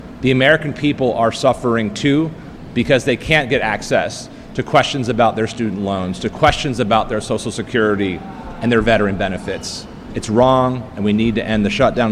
The Maryland Federal delegation and Governor Moore held a press conference at BWI Airport to discuss ongoing effects of the federal government shutdown. Air-Traffic Controllers at BWI are working without pay – some calling in sick – reducing staff and causing flight delays and cancellations.